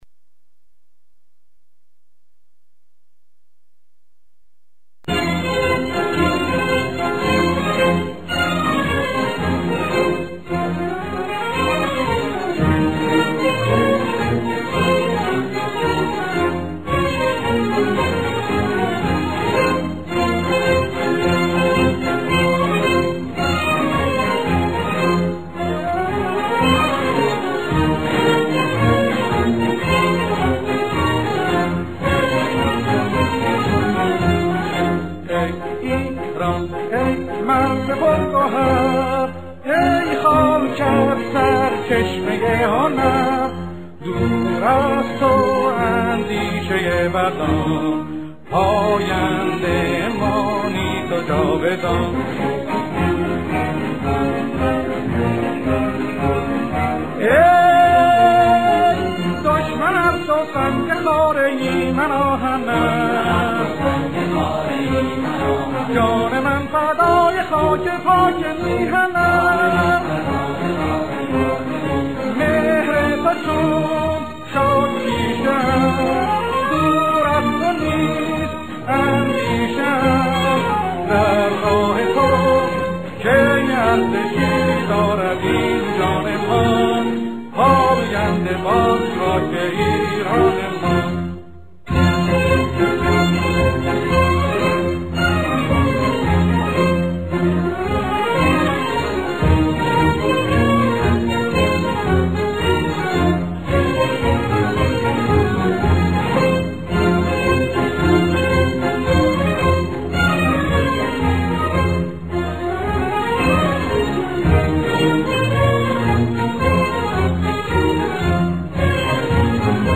در این فهرست، تعدادی از موسیقی‌های حماسی باکلام